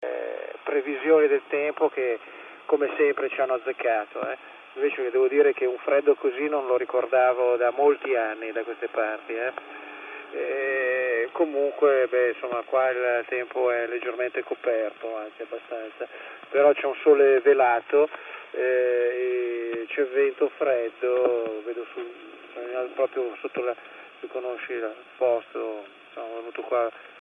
FM: Frequency Modulation -  Modulazione di Frequenza
FM in 144 MHz.mp3